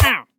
Minecraft Version Minecraft Version snapshot Latest Release | Latest Snapshot snapshot / assets / minecraft / sounds / mob / wandering_trader / hurt2.ogg Compare With Compare With Latest Release | Latest Snapshot
hurt2.ogg